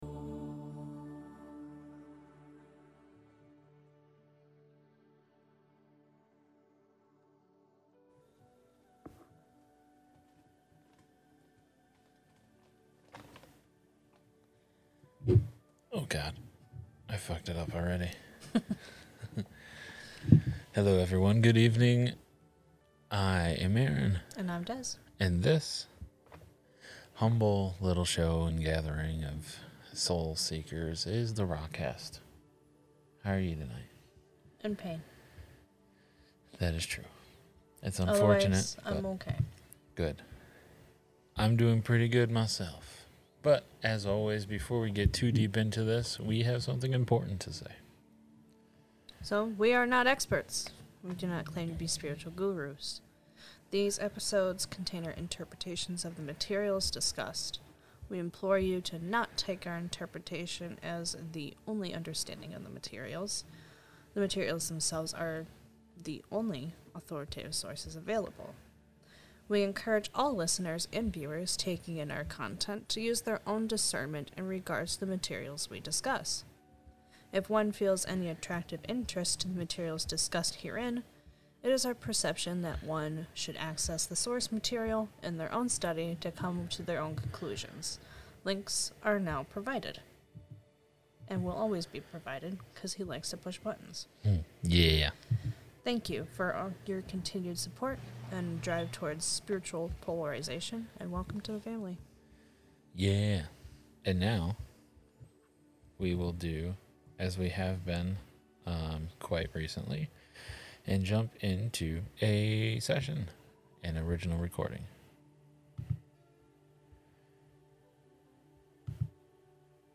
Catch the show LIVE